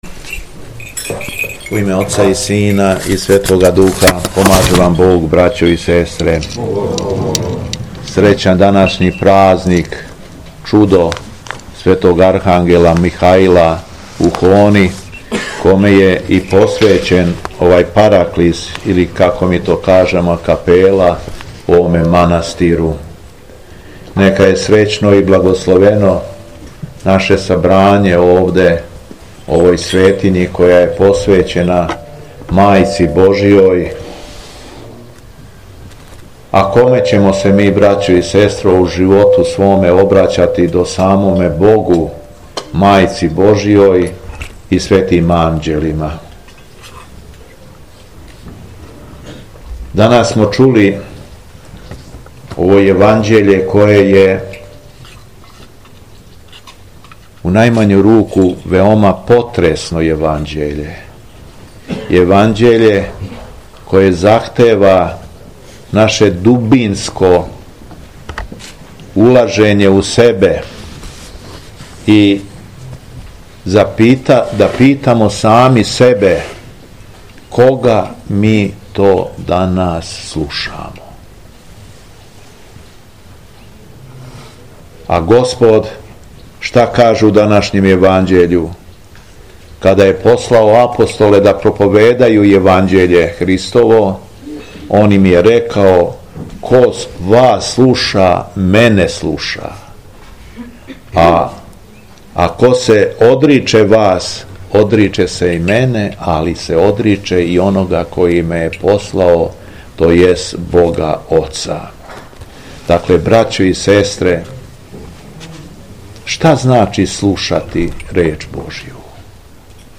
Беседа Његовог Високопреосвештенства Митрополита шумадијског г. Јована
Након прочитаног зачала из Светог Јеванђеља Митрополит се обратио верном народу речима: